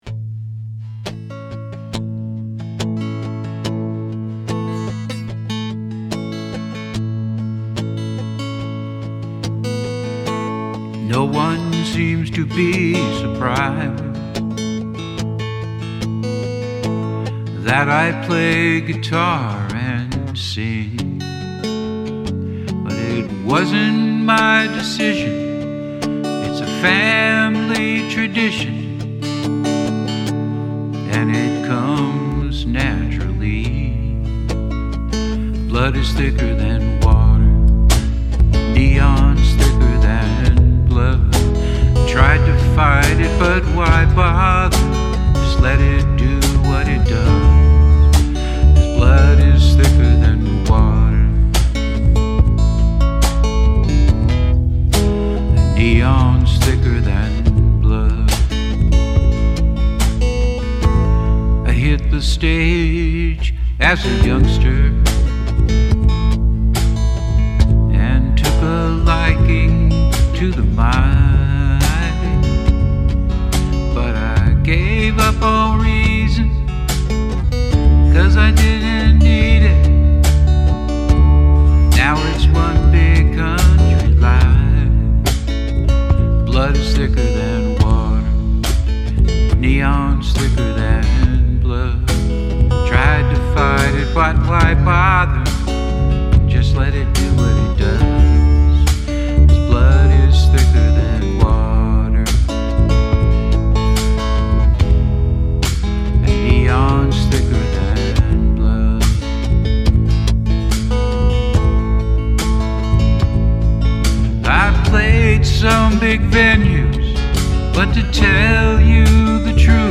I like the title but it took me awhile to interpret it, as blood is actually quite a bit thicker than neon..so i take it that this lad is more drawn to the city lights than the country family life. really nice guitar and vocal on the demo, and i appreciate the classic structure of the country song while the lyrics step outside of the usual cliches.